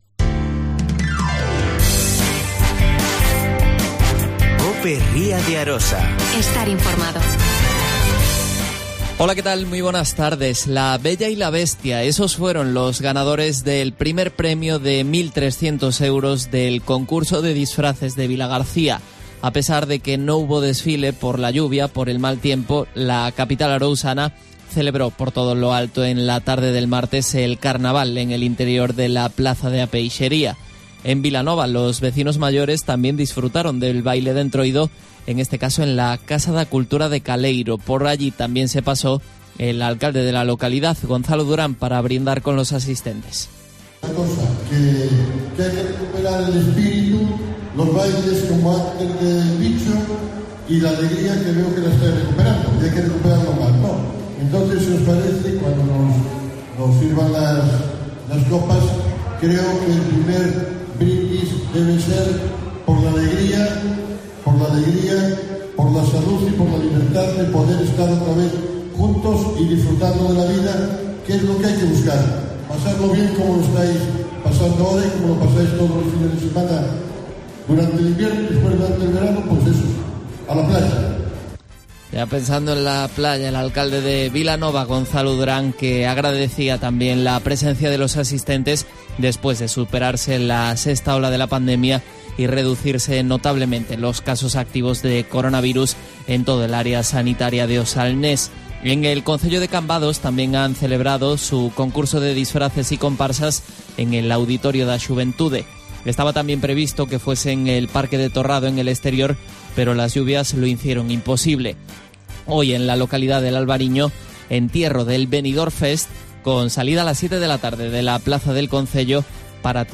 AUDIO: Tania García. Portavoz del Gobierno Local de Vilagarcía.